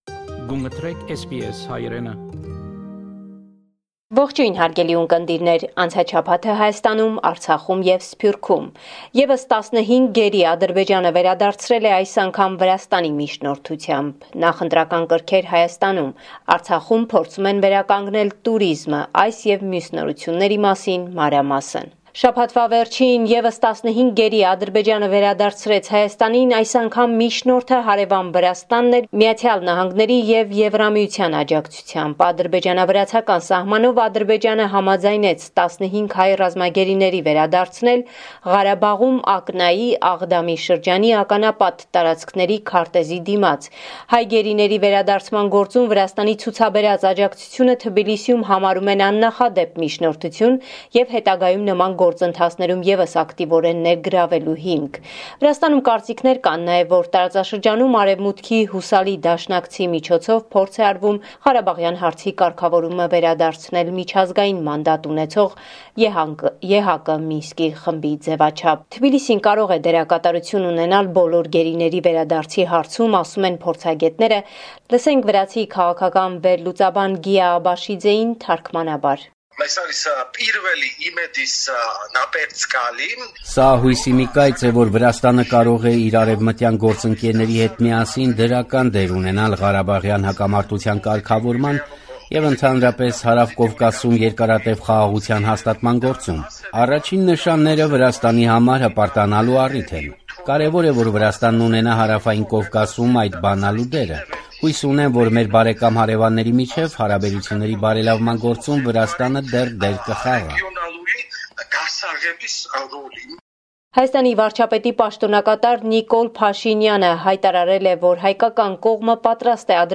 Latest News from Armenia – 15 June 2021